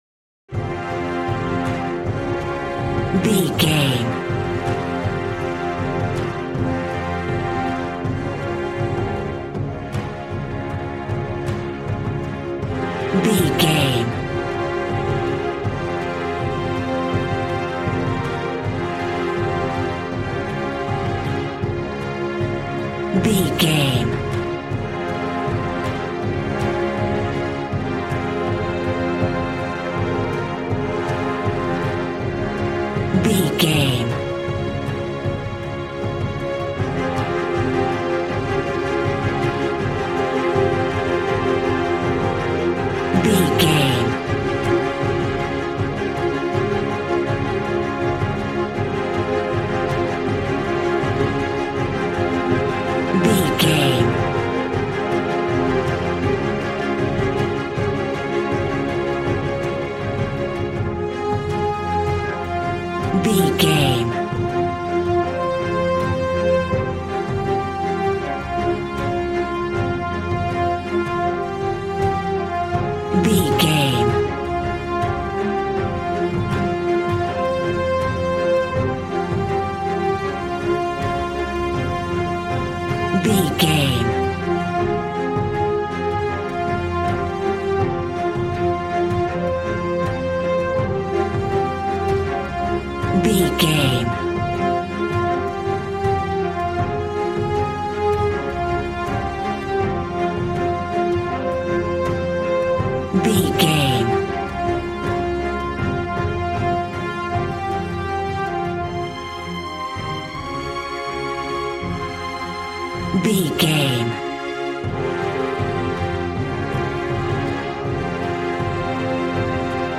Aeolian/Minor
suspense
piano
synthesiser